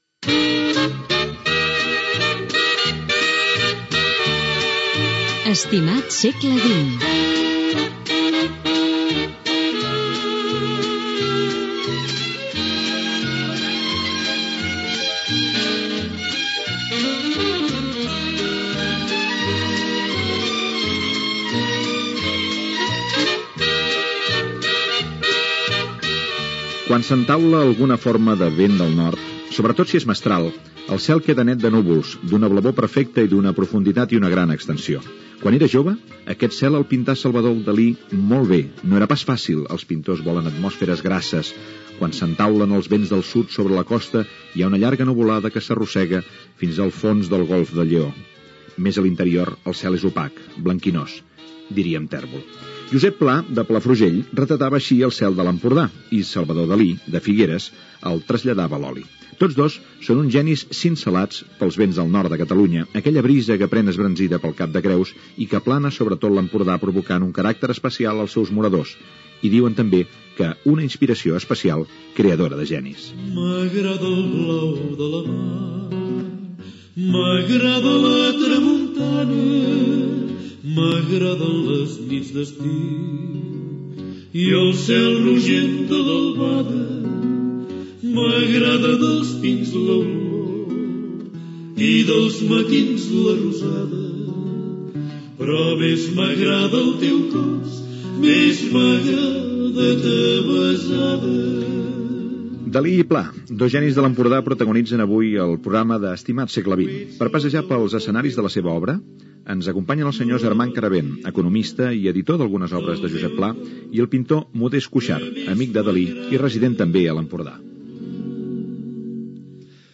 Careta del programa, perfil de Salvador Dalí i Josep Pla, havanera, conversa amb l'economista Armand Carabén i el pintor Modest Cuixart sobre Salvador Dalí i com el van conèixer